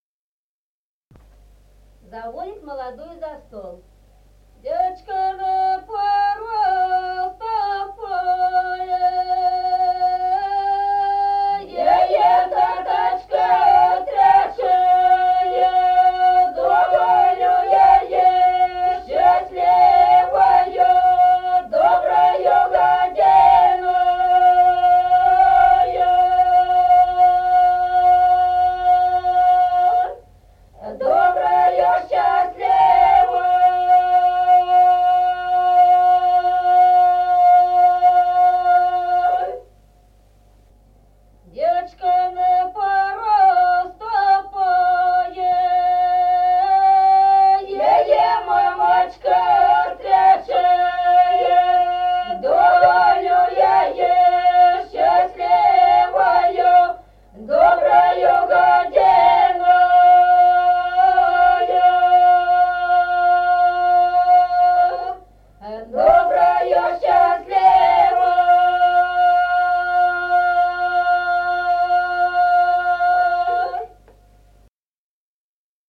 Народные песни Стародубского района «Девочка на порог ступае», свадебная, «заводять молодую за стол».
(запев)
(подголосник)
1953 г., с. Остроглядово.